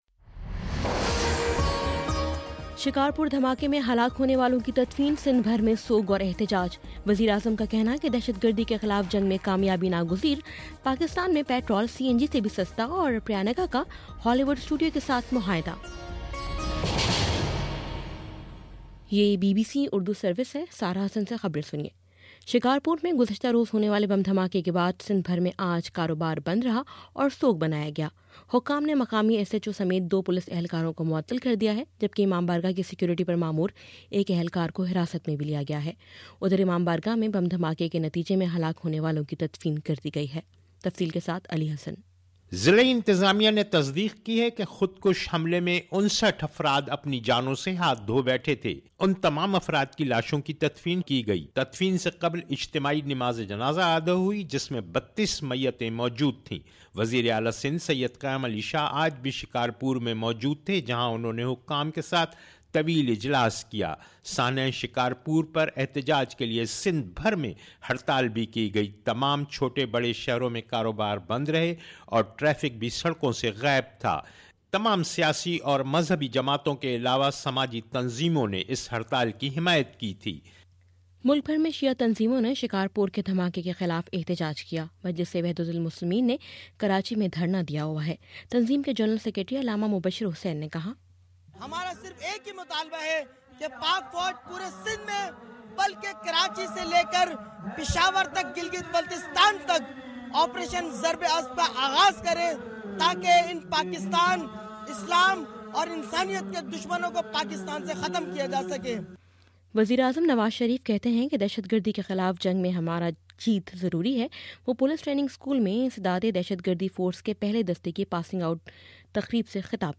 جنوری 31: شام چھ بجے کا نیوز بُلیٹن